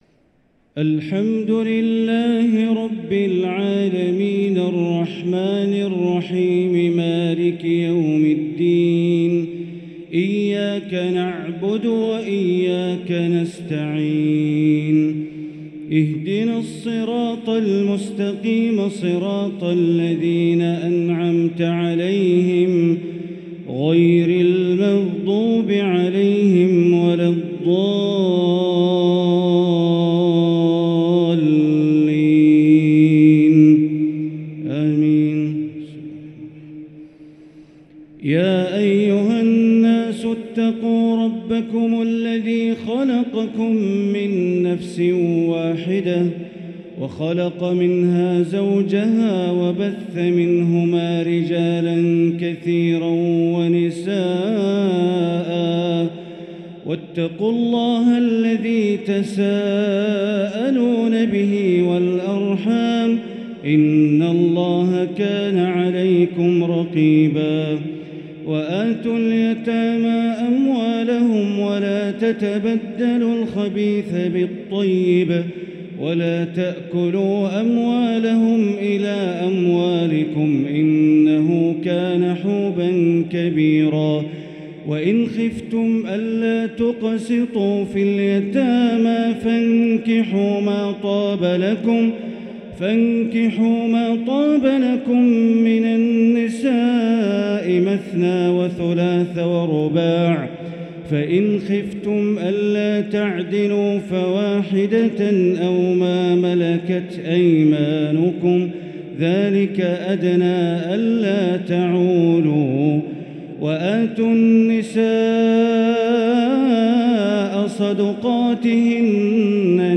تراويح ليلة 6 رمضان 1444هـ فواتح سورة النساء (1-42) | Taraweeh 6 st night Ramadan 1444H Surah An-Nisaa > تراويح الحرم المكي عام 1444 🕋 > التراويح - تلاوات الحرمين